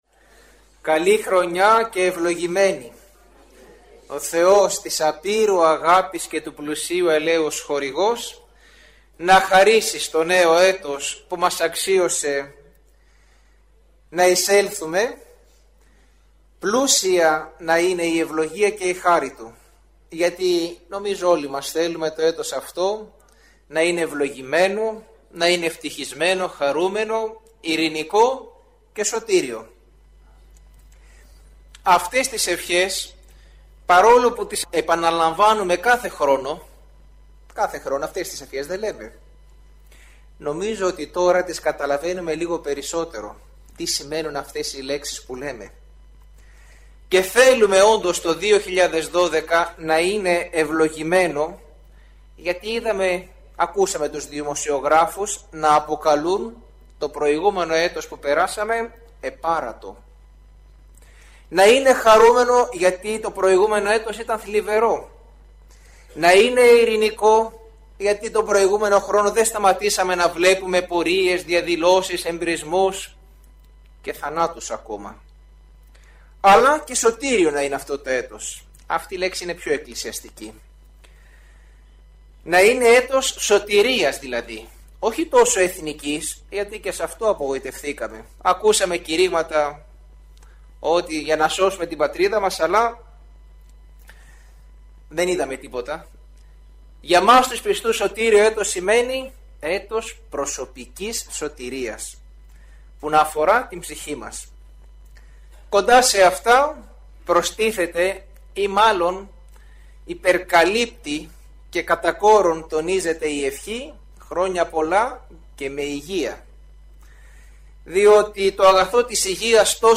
Η ομιλία εκφωνήθηκε στην Χριστιανική Ένωση Αγρινίου, στις 18 Ιανουαρίου του 2012.